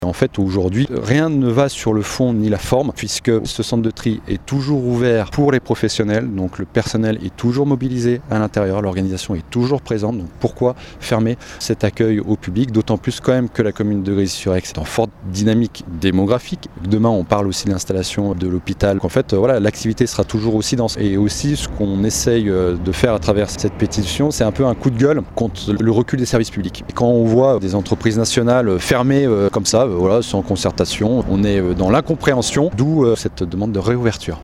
Laisser fermer ce centre de tri au public est une décision jugée incompréhensible aujourd’hui par la municipalité, d’autant qu’il reste ouvert aux professionnels. Florian Maitre, le maire de Grésy sur Aix s’en explique.